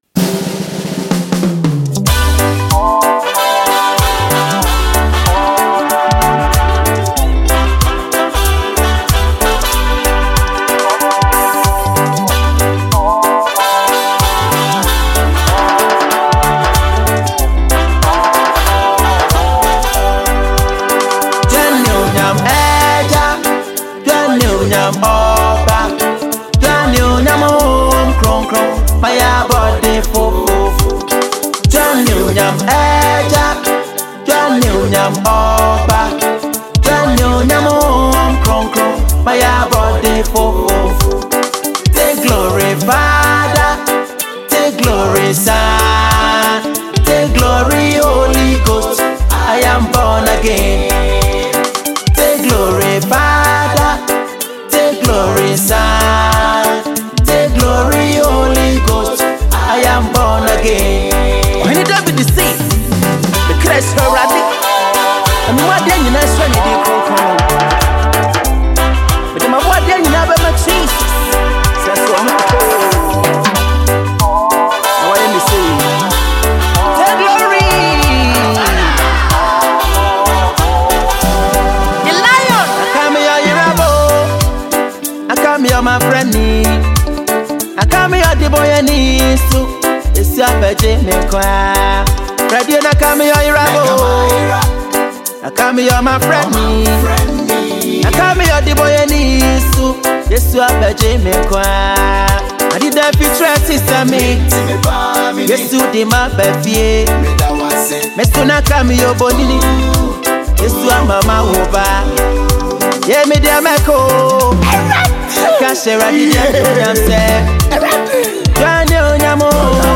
Ghanaian Gospel artist
gospel melody